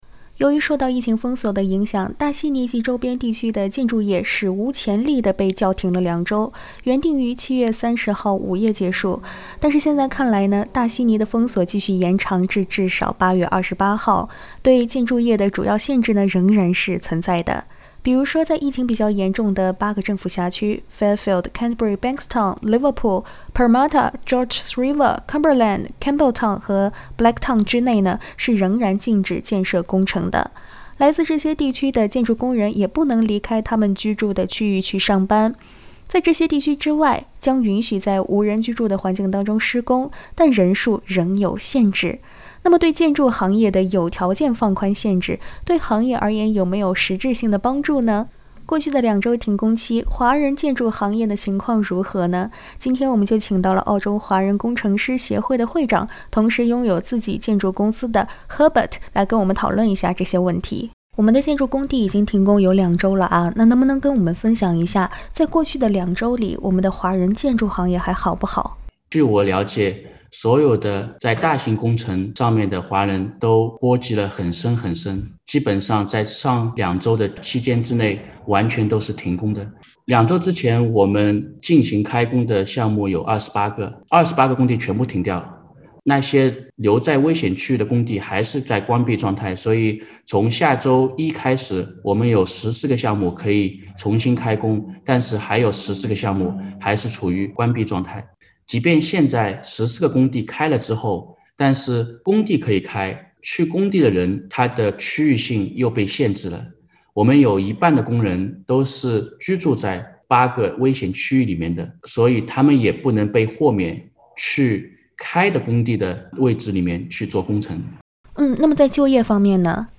（请听采访，本节目为嘉宾观点，不代表本台立场） 澳大利亚人必须与他人保持至少1.5米的社交距离，请查看您所在州或领地的最新社交限制措施。